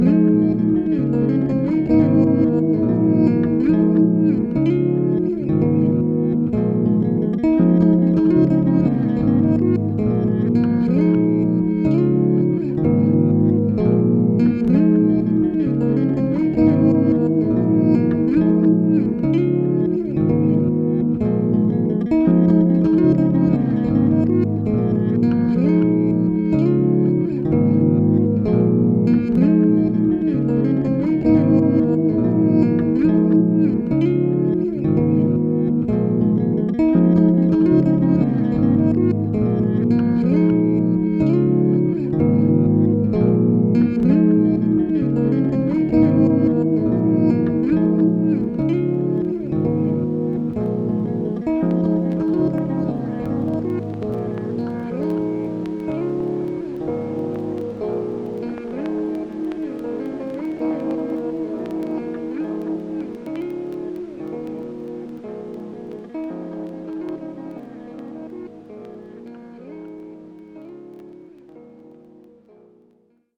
A quick snippet on the tenor bass.